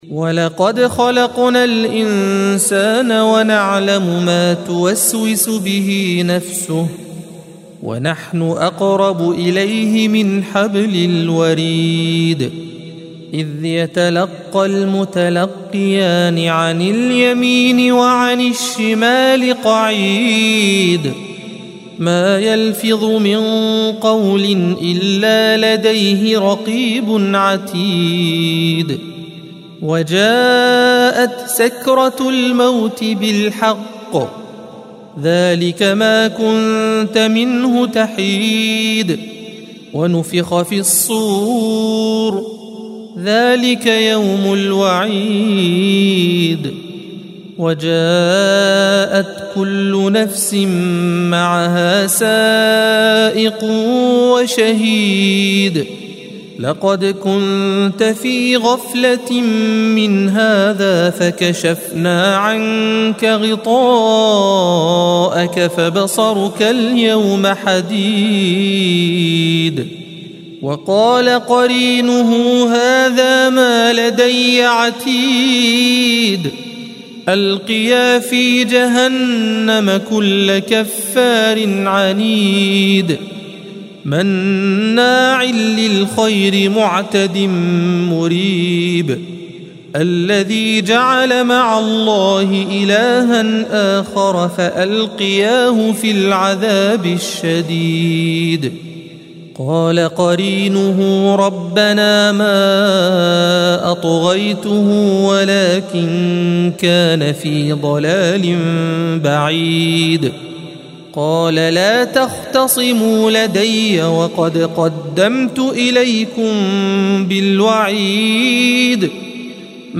الصفحة 519 - القارئ